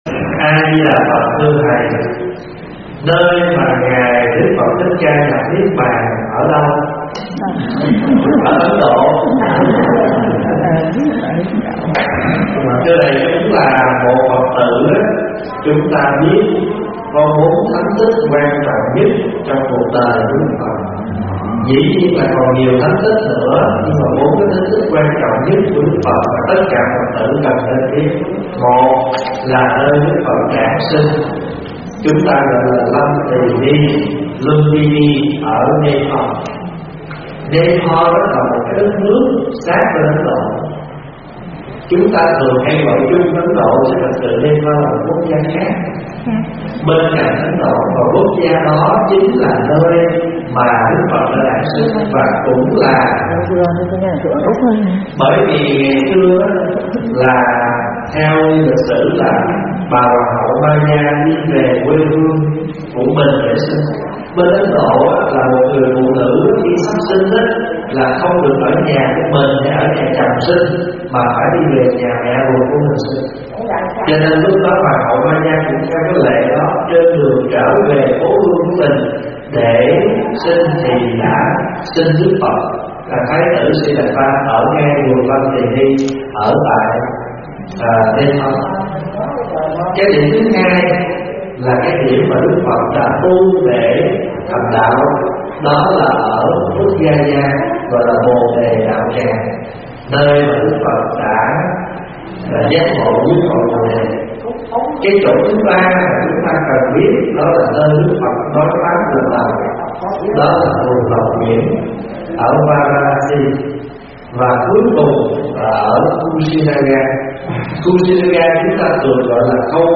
Vấn đáp Nơi Đức Phật Nhập Niết Bàn - ĐĐ.